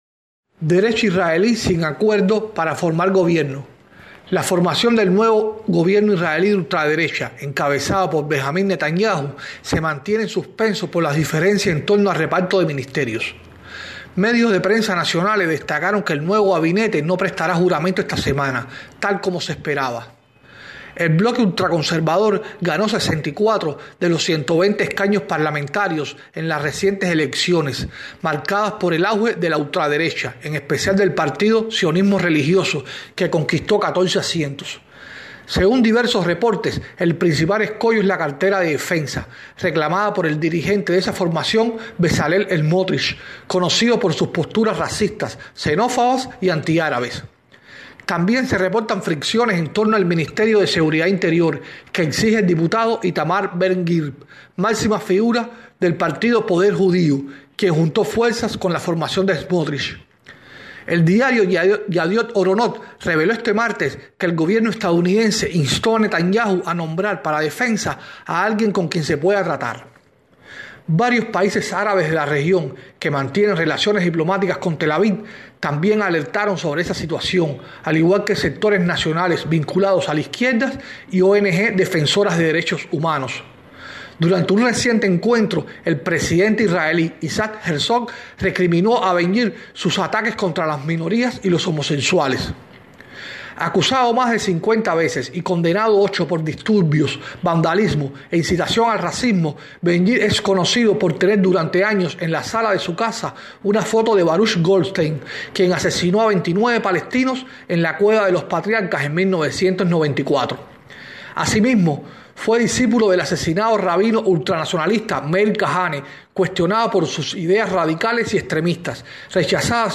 desde El Cairo